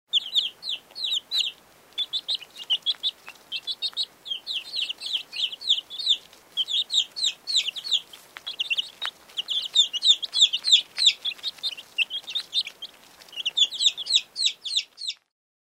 На этой странице собраны разнообразные звуки цыплят: от милого писка до забавного квохтания.
Писк двухдневных цыплят